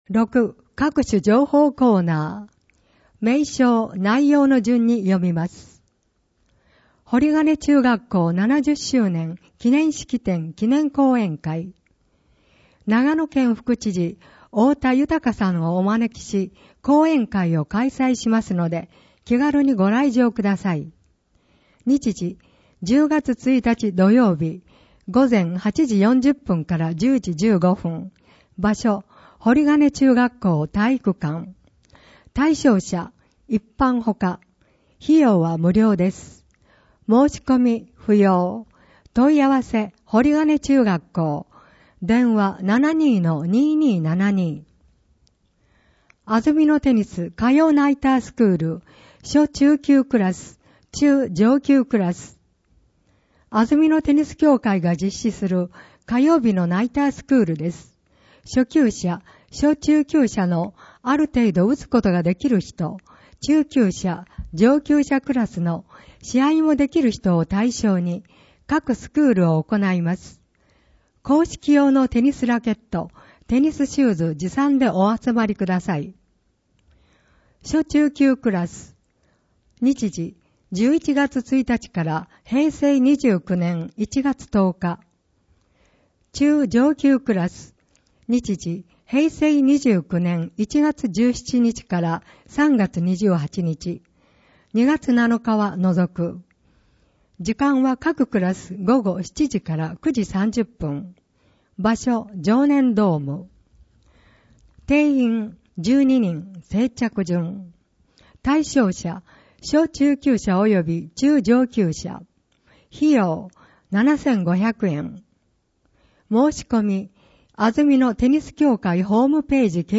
広報あづみの朗読版234号（平成28年9月7日発行)
この録音図書は、安曇野市中央図書館が制作しています。